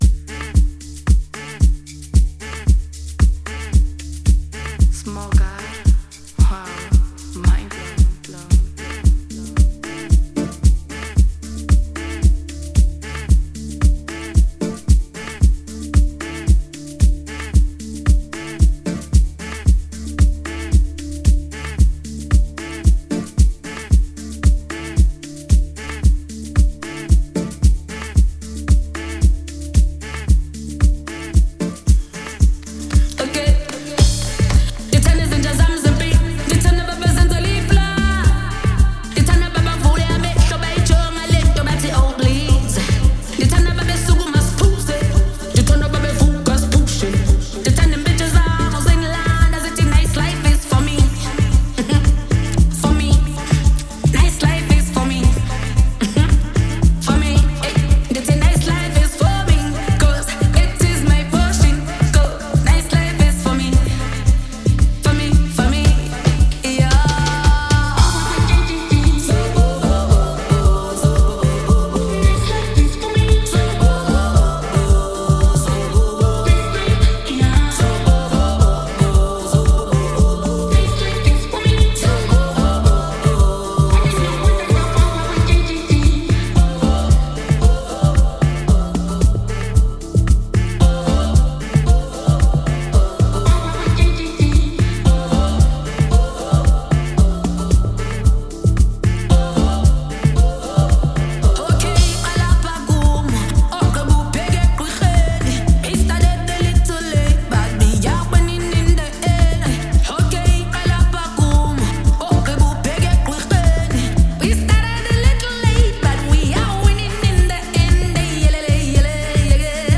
South African female singer